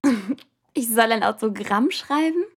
1½ Ritter – Auf der Suche nach der hinreißenden Herzelinde Sample 3 Datei herunterladen weitere Infos zum Spiel in unserer Spieleliste Beschreibung: Drittes Sprachbeispiel zur Rolle der Herzelinde.